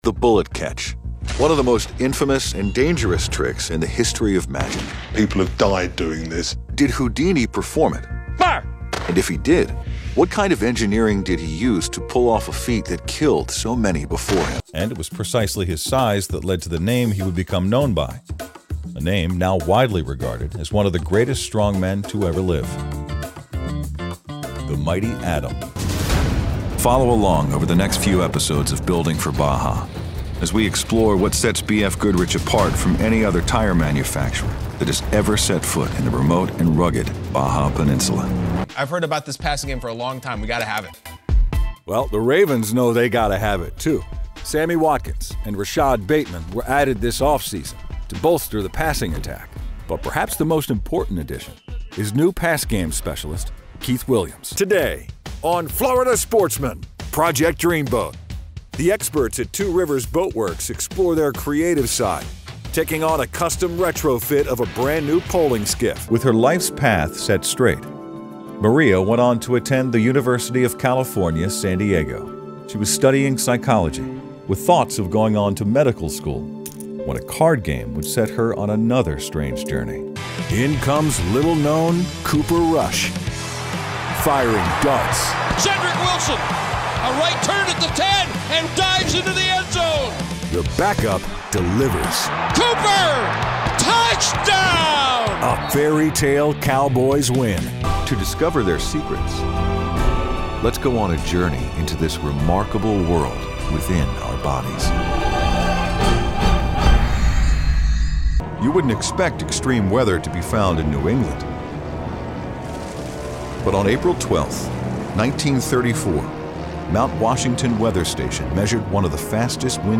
Rugged. Warm
In Show Demo
Middle Aged